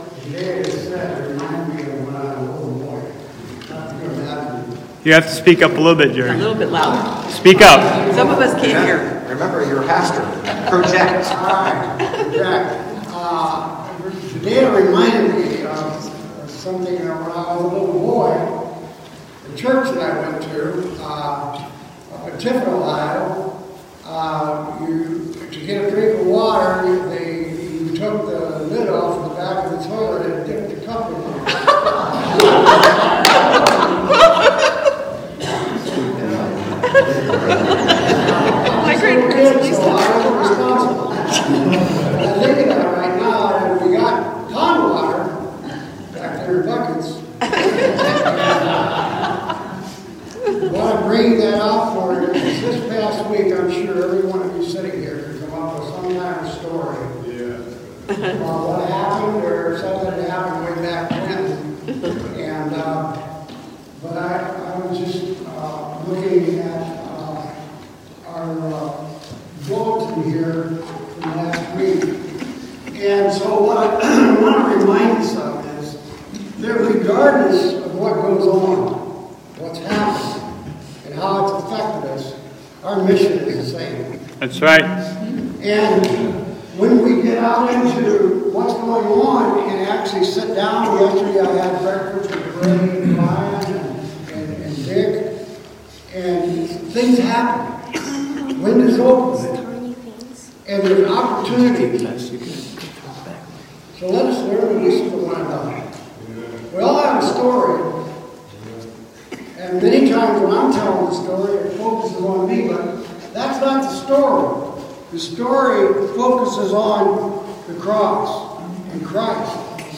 This week's message is special because it centers on the father's in our lives. But also, because a strong many arrived to have church together although the building was without power due to the previous week's storms.
Please note: The following videos are from a recording on a mobile phone.